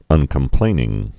(ŭnkəm-plānĭng)